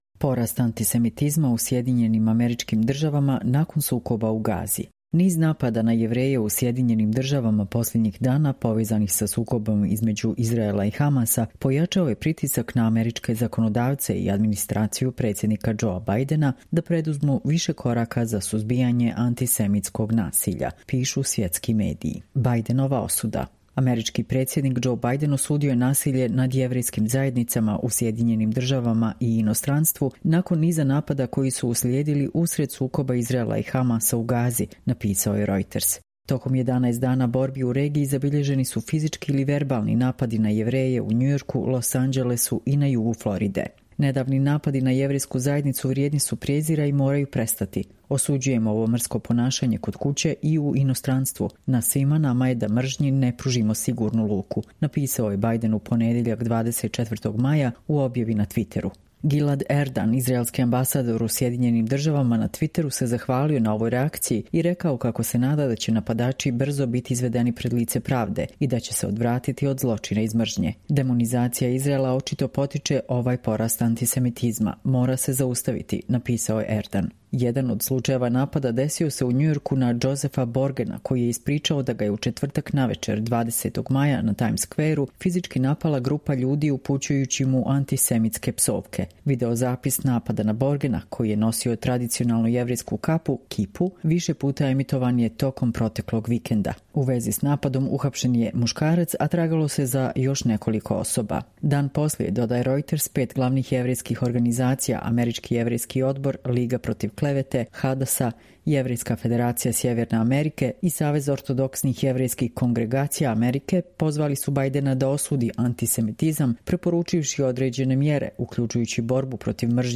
Čitamo vam: Porast antisemitizma u SAD nakon sukoba u Gazi